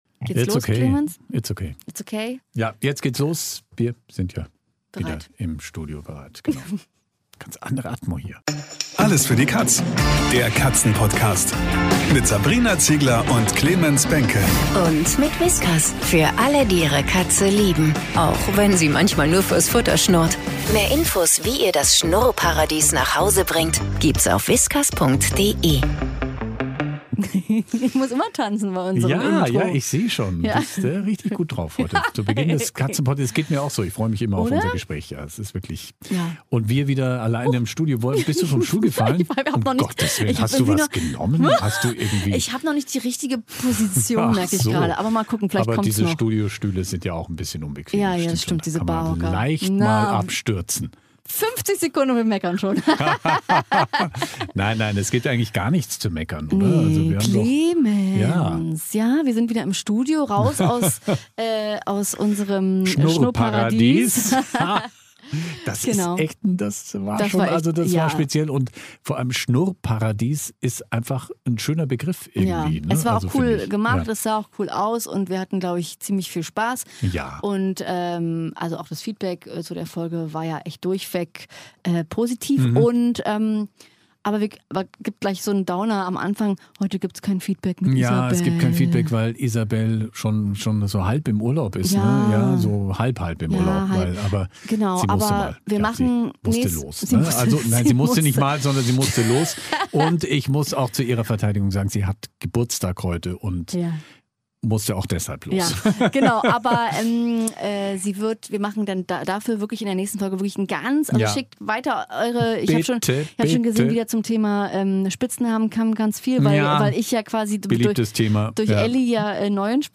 Kater Lui sorgt wie immer für ordentlich Chaos, besonders bei der Futterfrage. Im Interview ist das Tierheim Oldenburg zu Gast und erzählt uns alles über die geheimnisvollen "Schattenkatzen".